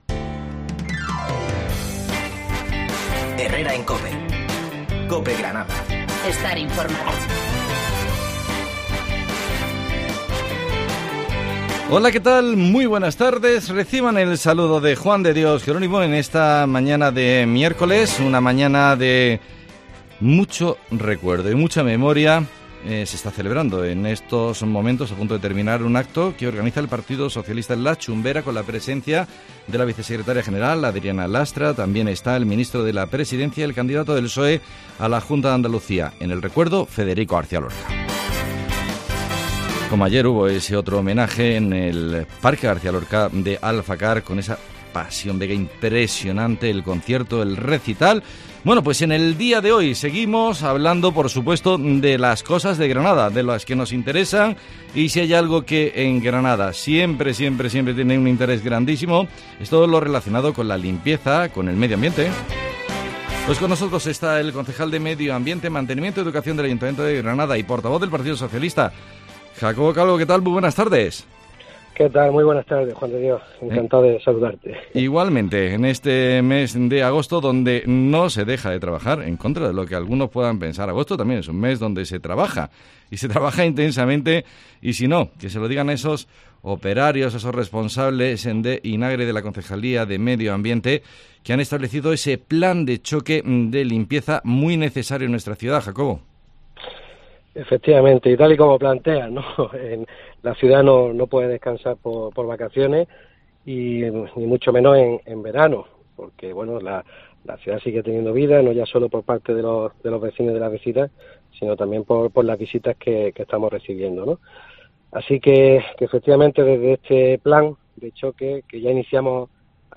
El concejal de Medioambiente nos detalla el plan urgente de limpieza de Granada en verano